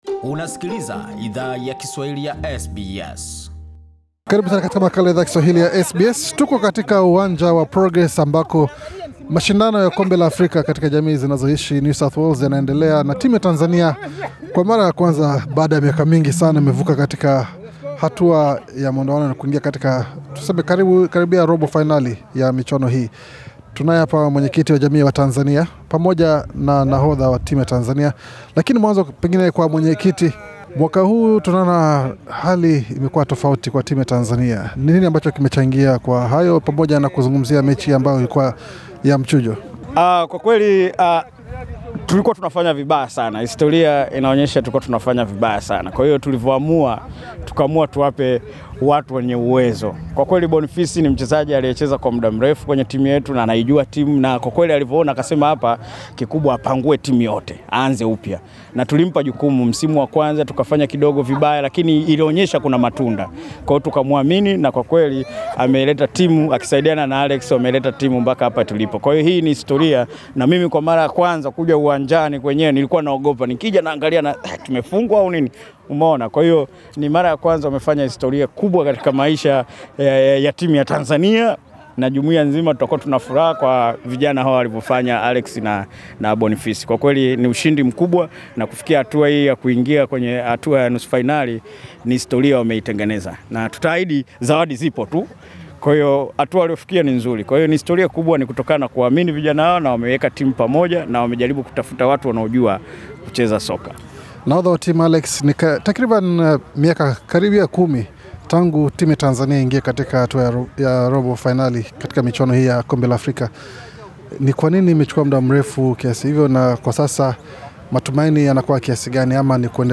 Source: SBS Swahili Idhaa ya Kiswahili ya SBS, ilizungumza na viongozi wa jamii na timu hiyo, kuhusu hatua hiyo yakihistoria kwa timu ya Tanzania katika michuano hiyo.